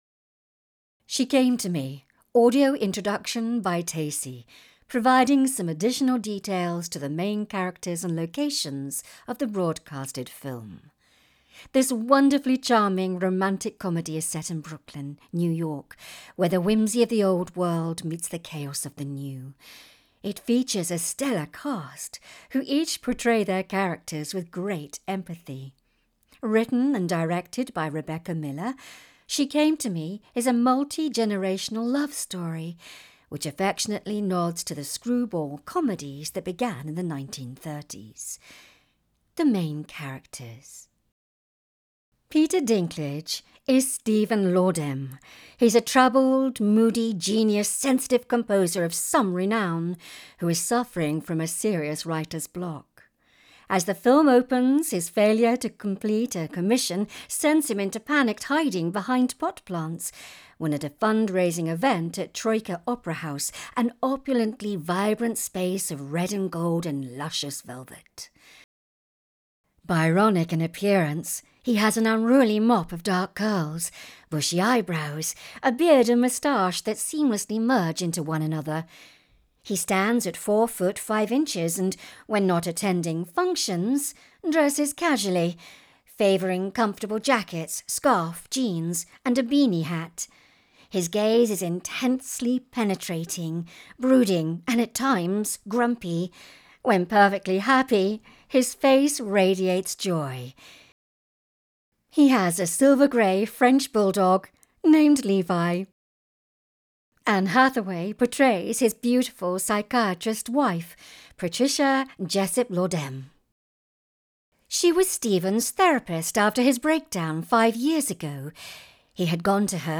She Came To Me - AD Introduction
She_Came_To_Me_AD_Intro.wav